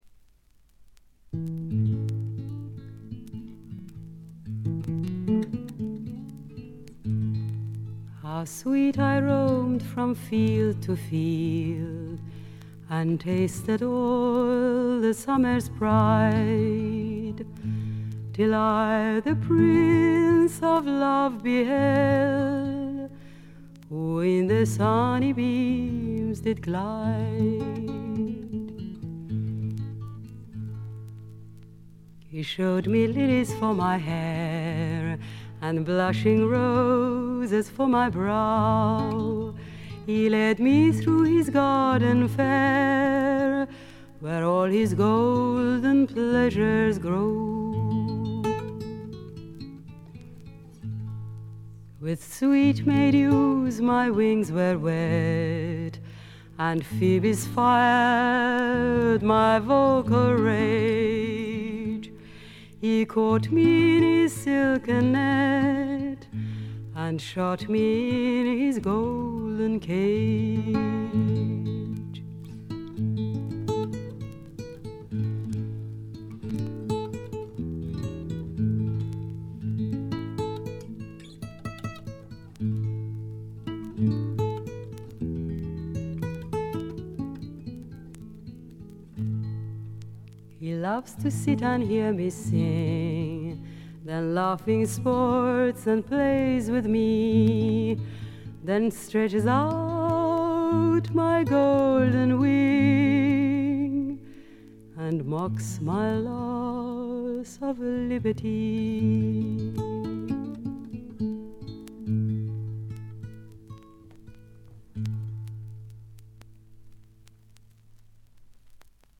静音部でのバックグラウンドノイズ、軽微なチリプチ。
ともあれ、どんな曲をやってもぞくぞくするようなアルトヴォイスがすべてを持って行ってしまいますね。
試聴曲は現品からの取り込み音源です。